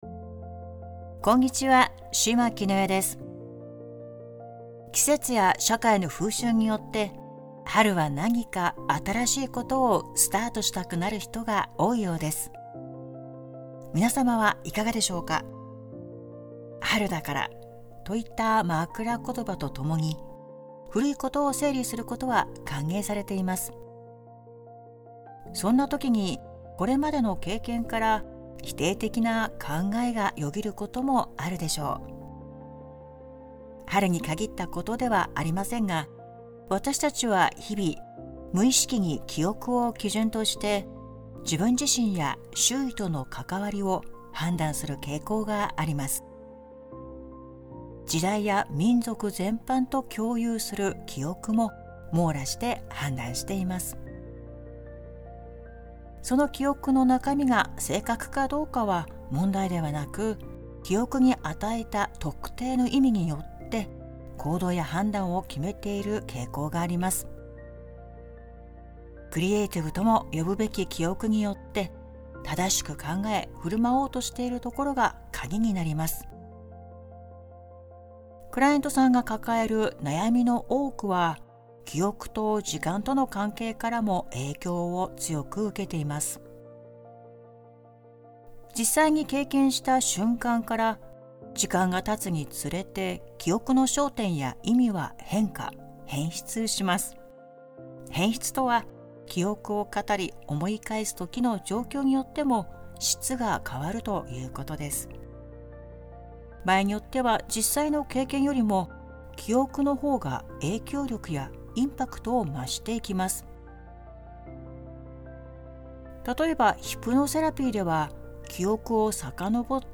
※ [ 5:55頃 ] 〈記憶との関わりを変えてみる〉誘導ワーク♪